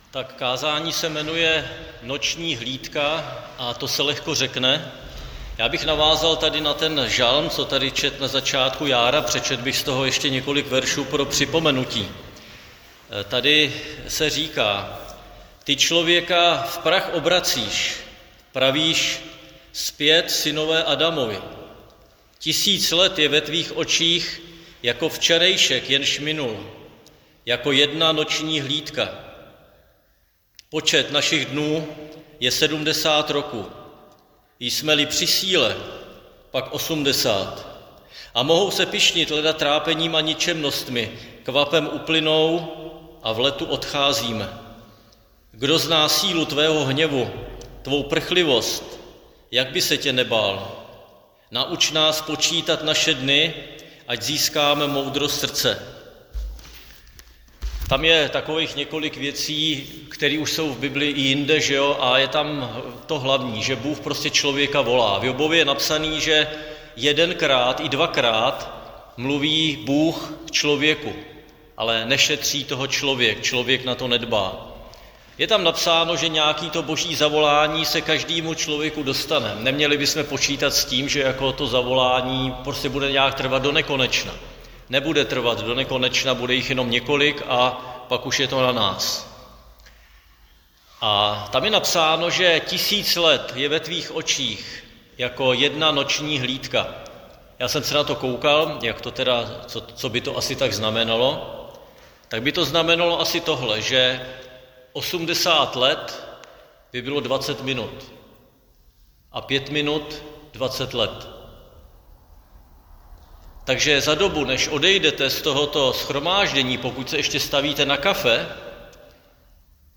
Křesťanské společenství Jičín - Kázání 29.6.2024